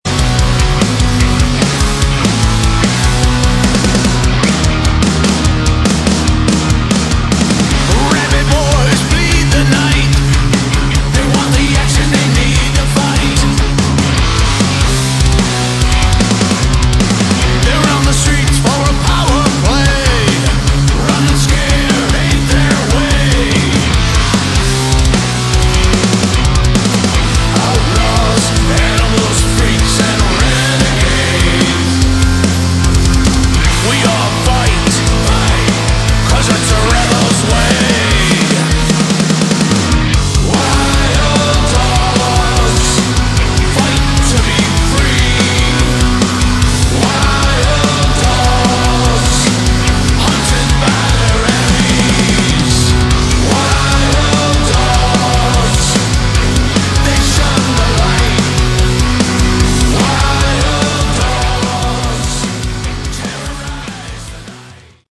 Category: Melodic Metal
guitar, vocals
bass
drums